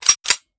fps_project_1/45 ACP 1911 Pistol - Cocking Slide 004.wav at fc29636ee627f31deb239db9fb1118c9b5ec4b9f - fps_project_1 - Gitea: Git with a cup of tea